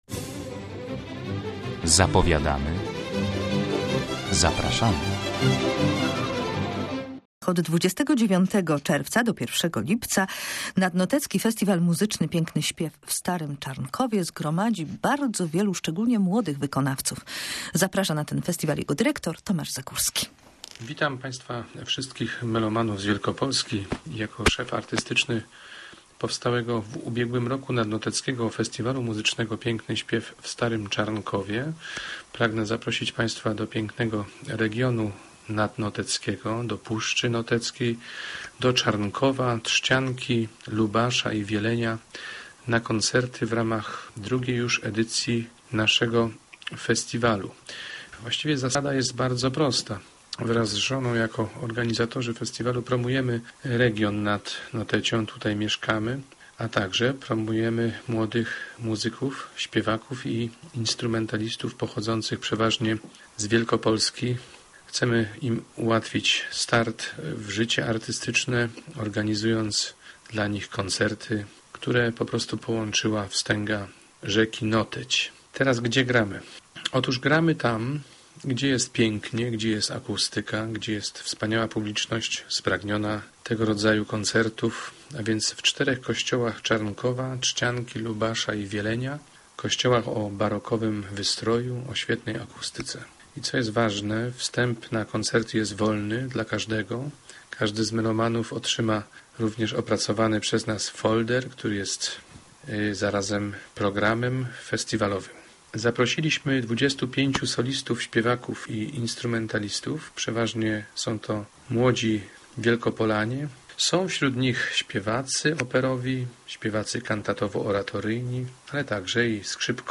Piękny śpiew w starym Czarnkowie